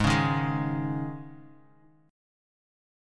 G#sus4 chord